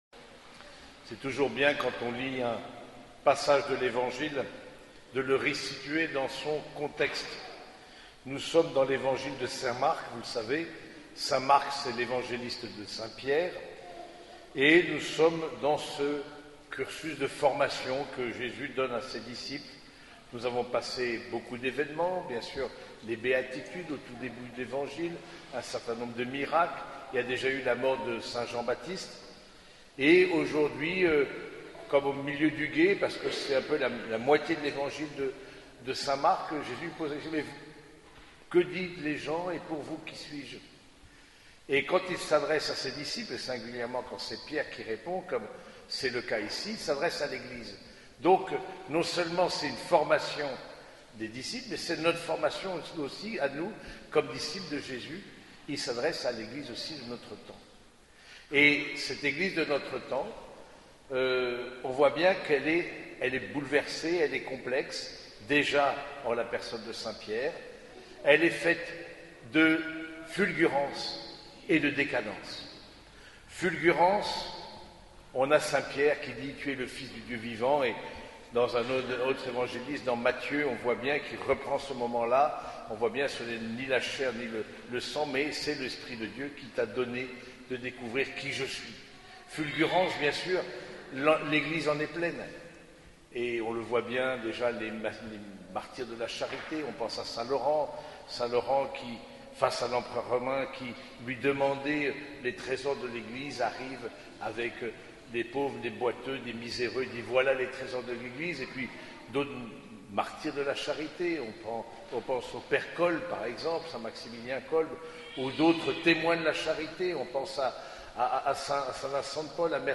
Homélie du 24e dimanche du Temps Ordinaire